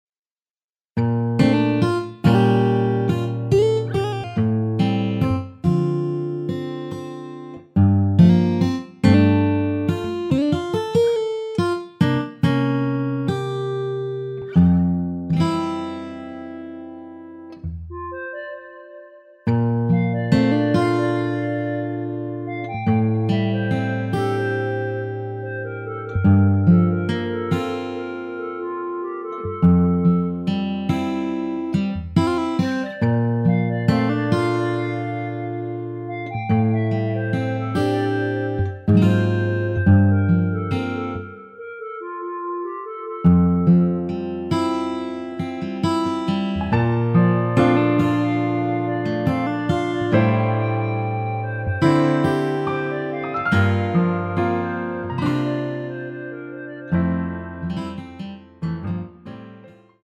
Bb
멜로디 MR이라고 합니다.
앞부분30초, 뒷부분30초씩 편집해서 올려 드리고 있습니다.
중간에 음이 끈어지고 다시 나오는 이유는